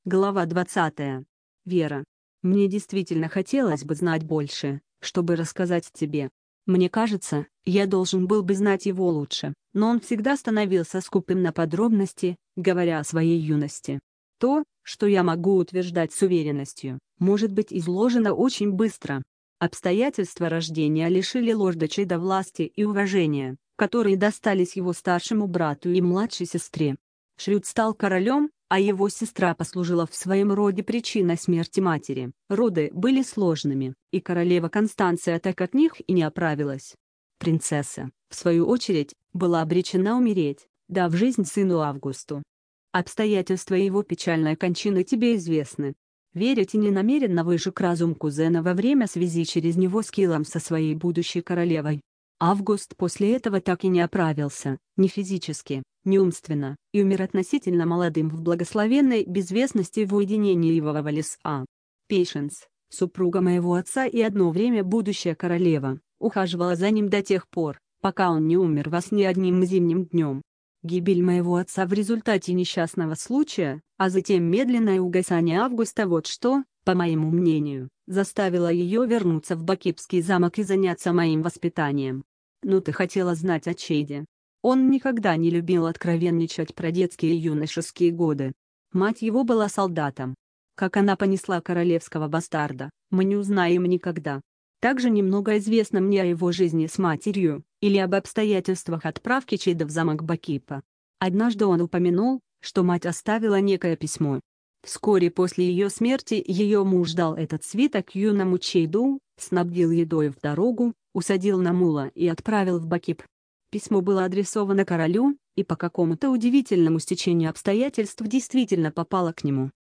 Здесь представлены образцы звучания русских голосов.
{Ivona Татьяна} Р. Хобб - Судьба Убийцы
20_tatyana.mp3